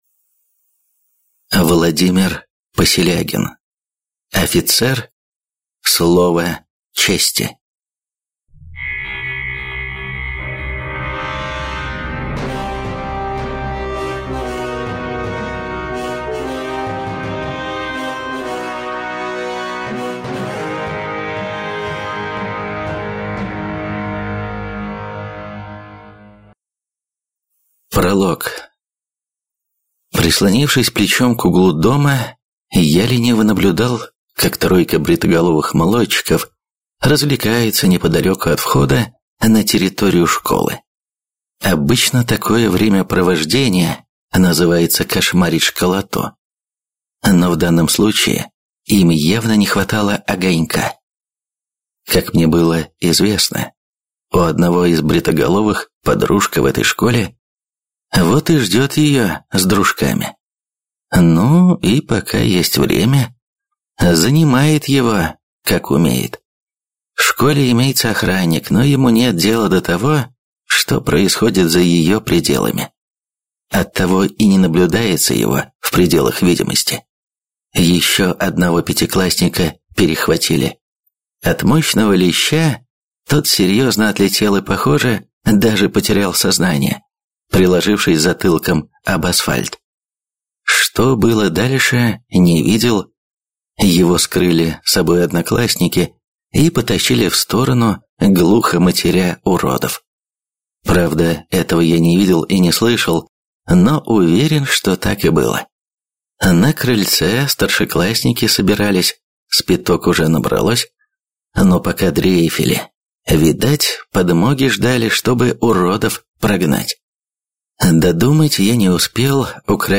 Аудиокнига Офицер. Слово чести | Библиотека аудиокниг